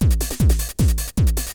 techno1.wav